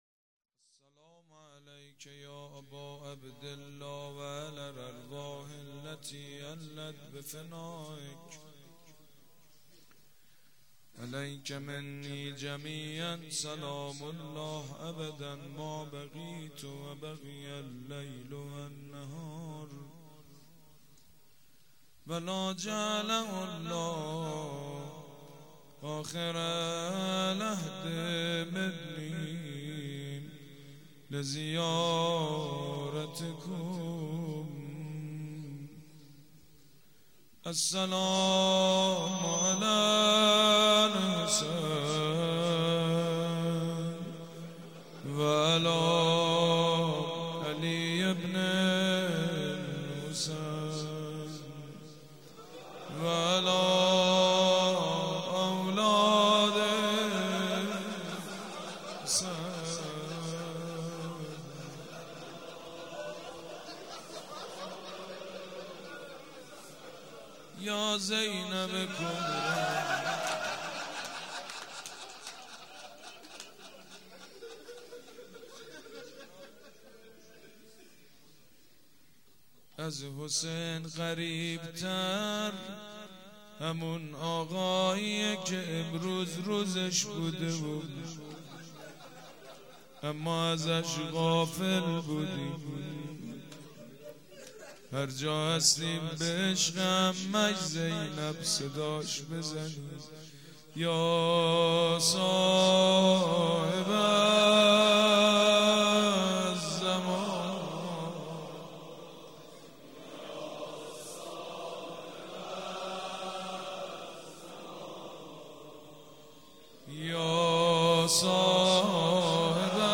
شب شهادت حضرت زينب(س) جمعه ٣ ارديبهشت ماه ١٣٩٥ هيئت ريحانة الحسين(س)
سبک اثــر روضه مداح حاج سید مجید بنی فاطمه
روضه_میبینی از داغ فراغت شکسته ام.mp3